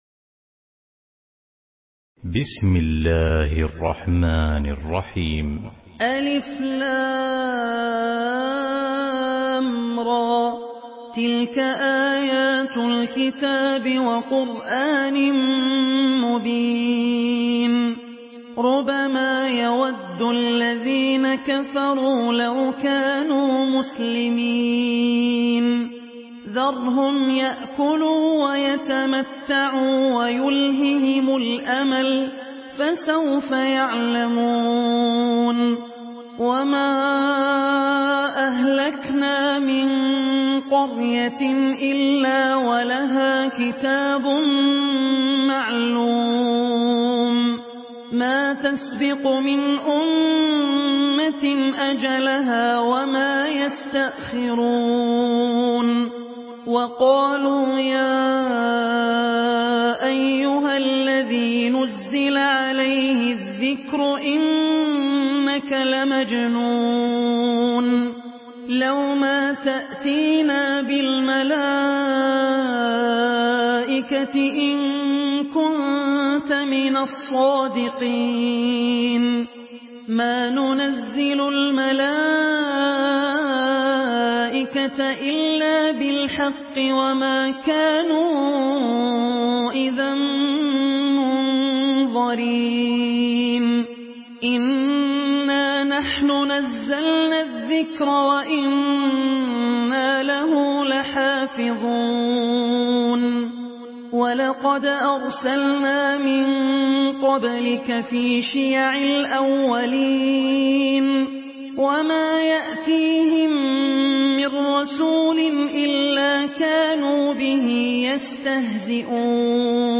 Surah Al-Hijr MP3 in the Voice of Abdul Rahman Al Ossi in Hafs Narration
Murattal Hafs An Asim